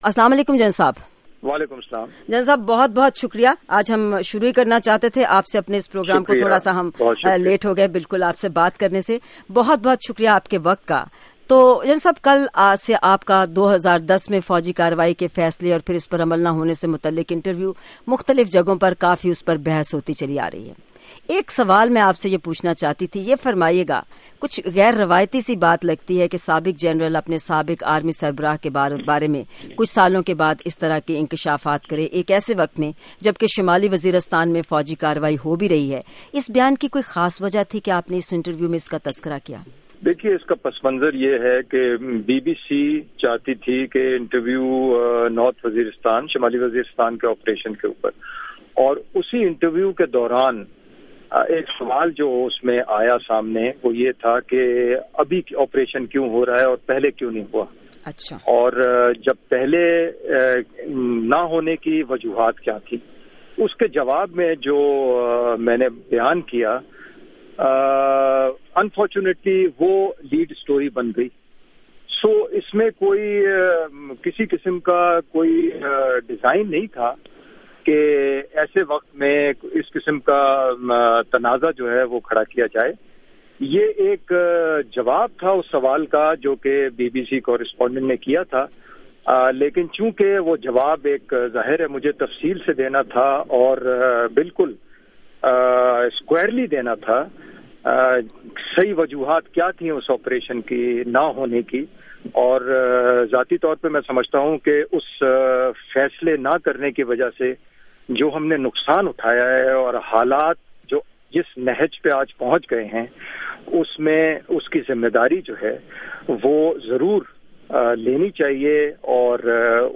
پاکستان فوج کے سابق ترجمان، ریٹائرڈ میجر جنرل اطہر عباس کا انٹرویو